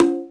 HR16B CONGA1.wav